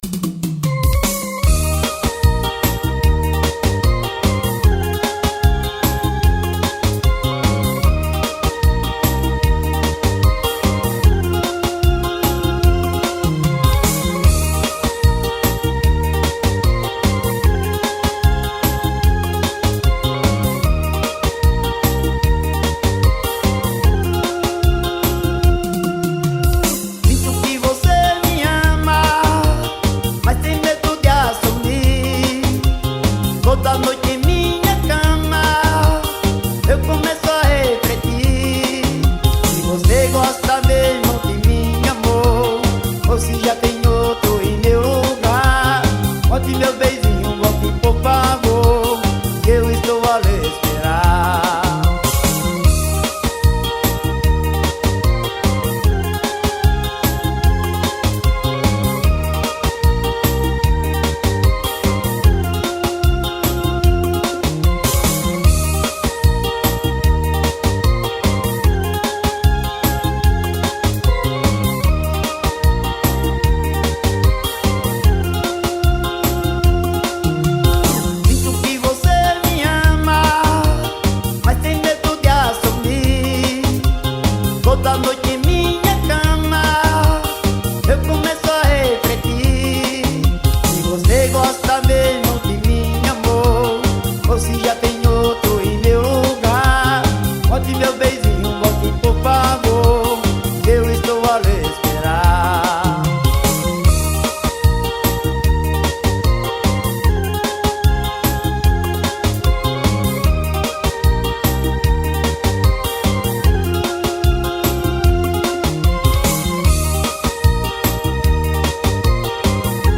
2025-01-03 17:54:47 Gênero: Sertanejo Views